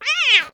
Angry Cartoon Kitty Meow Sound Button - Free Download & Play